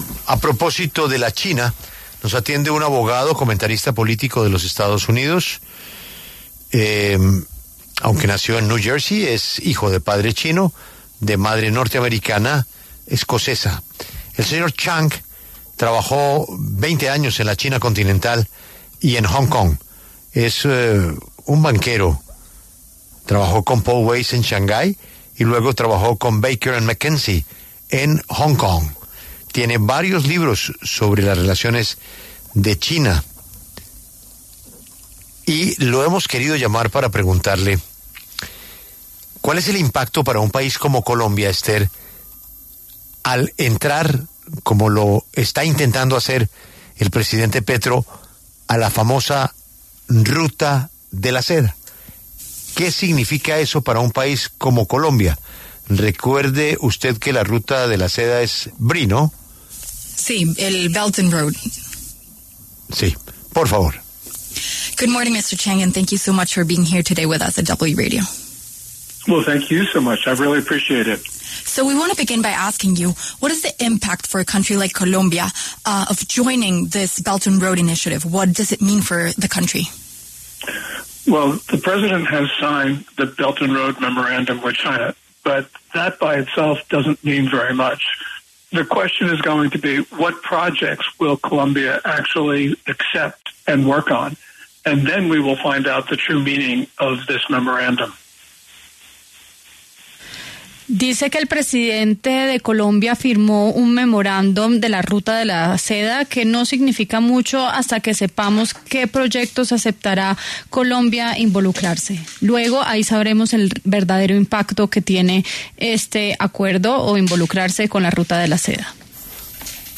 Gordon Chang, abogado y comentarista político estadounidense, habló en La W sobre los impactos para Colombia al entrar a la Ruta de la Seda de China.